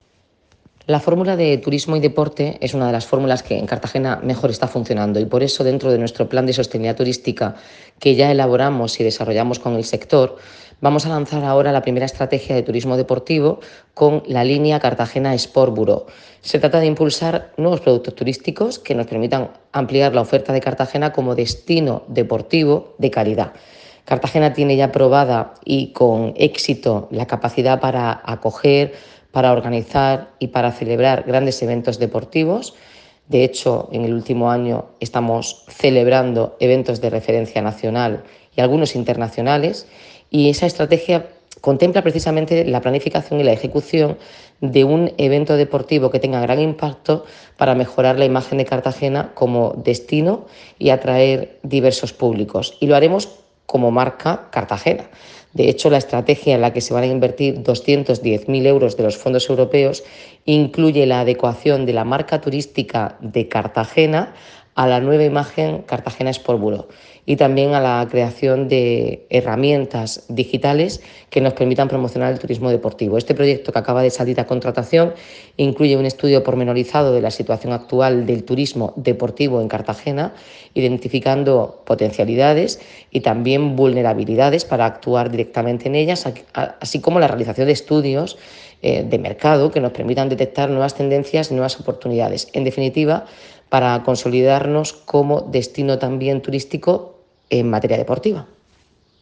Enlace a Delcaraciones de la alcaldesa, Noelia Arroyo.